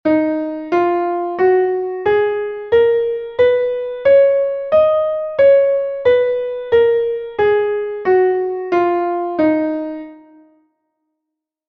Mi+B+Menor (audio/mpeg)